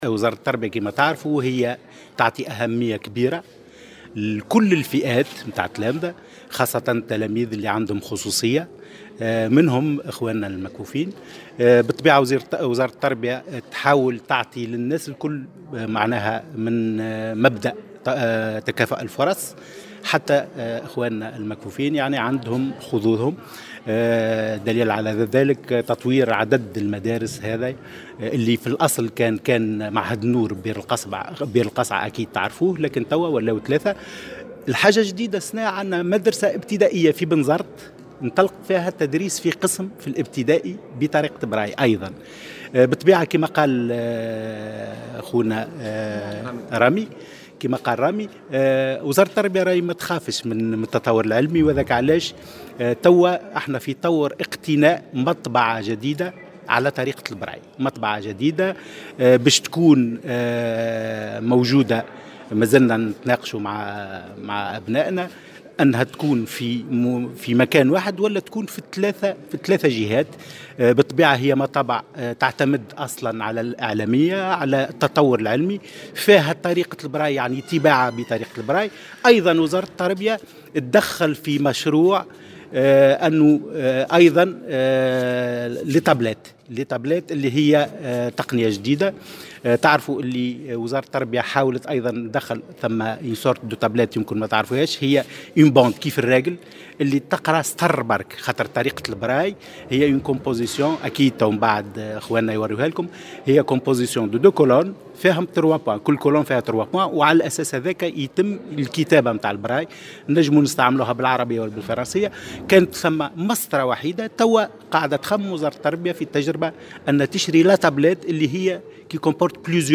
في تصريح لمراسل الجوهرة أف أم، على هامش تظاهرة بمناسبة اليوم العالمي للبرايل